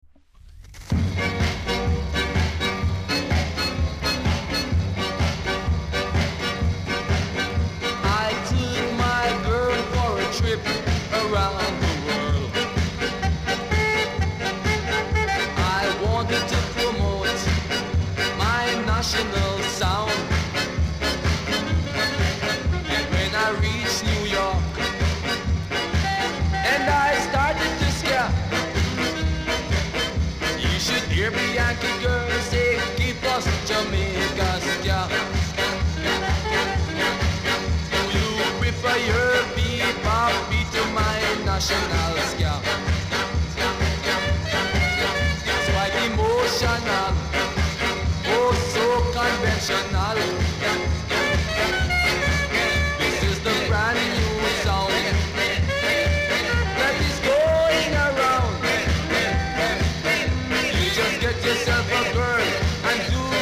※小さなチリノイズが少しあります。
両面BIG SKA!!